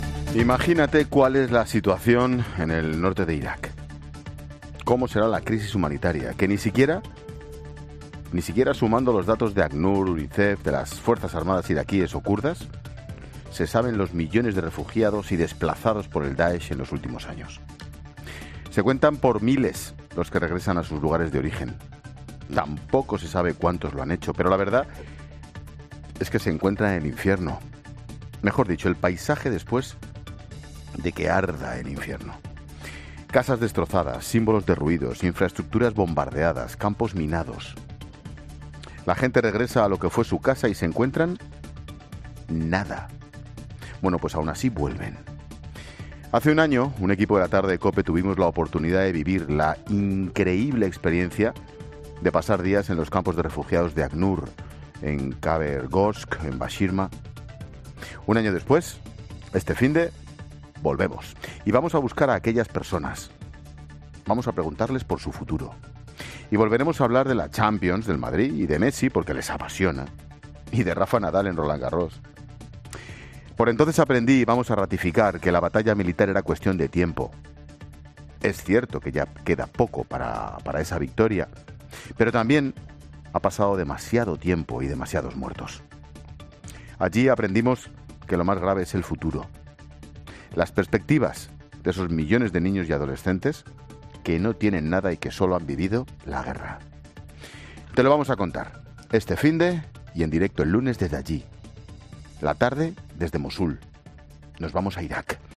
AUDIO: Monólogo de Ángel Expósito a las 17h. analizando las perspectivas de la generación que solo ha vivido la guerra en Irak y Siria.